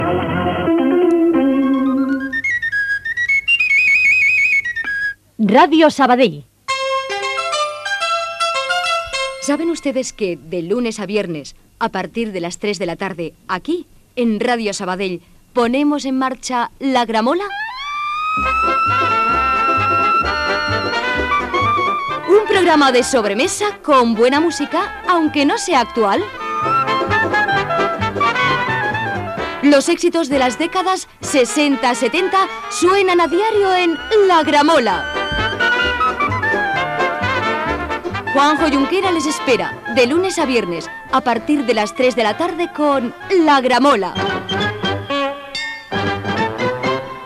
Sintonia de la cadena COPE, indicatiu de Ràdio Sabadell i promoció de "La gramola"